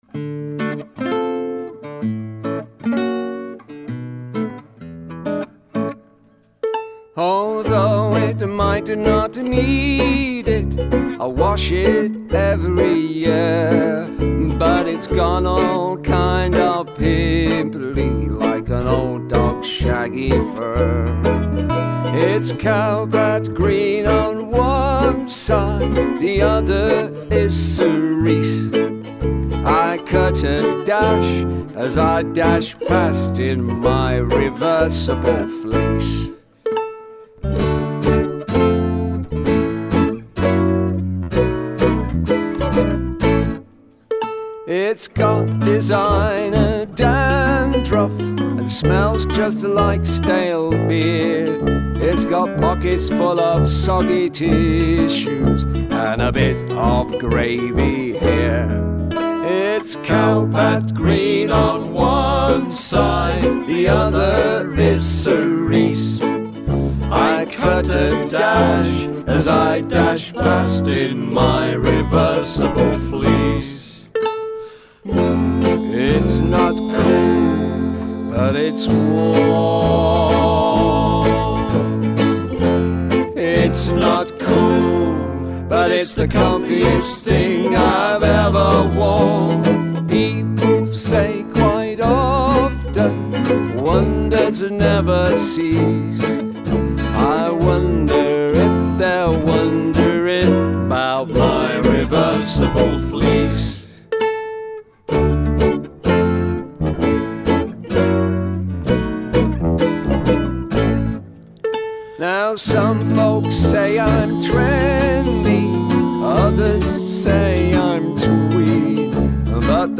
with a big Ukulele